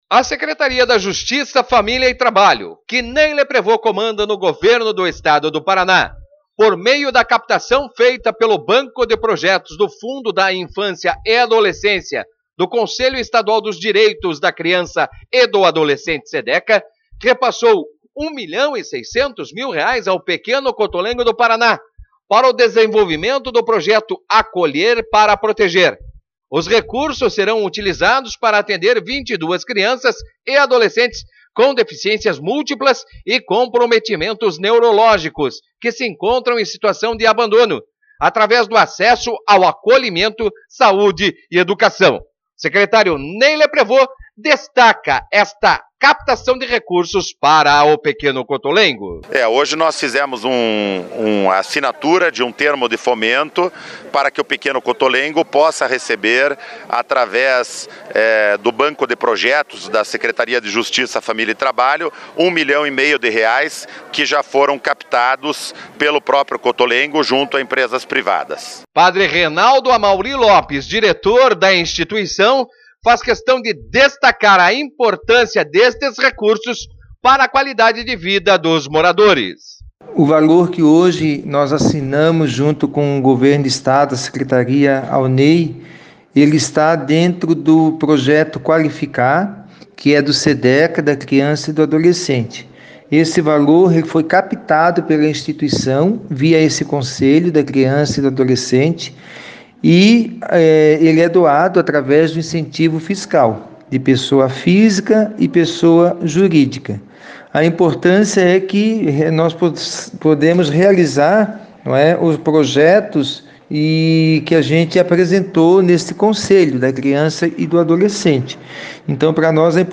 Secretário Ney Leprevost fala dos recursos repassados ao Pequeno Cotolengo